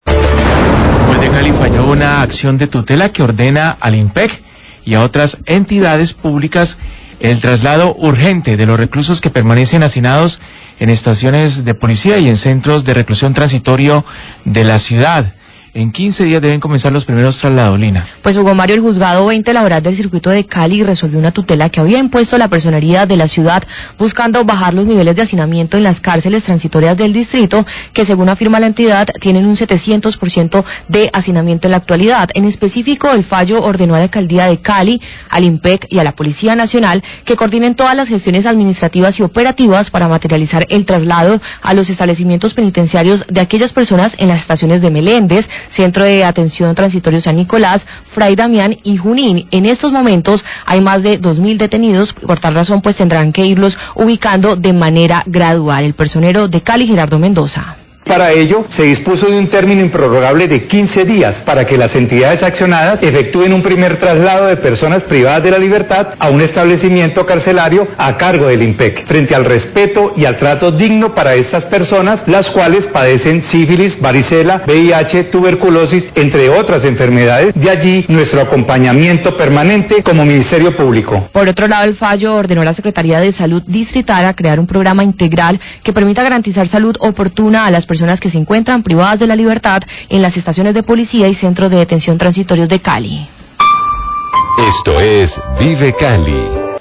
Personero Cali habla del fallo de tutela a favor del traslado de reclusos por hacinamiento,
Radio
El Personero de Cali, Gerardo Mendoza, habla de la tutela quie ordena al INPEC, a la Alcaldía de Cali y a la Policía Nacional, el traslado de los reclusos que se encuentran hacinados y enfermos en centros de  atención transitorios y estaciones de policía.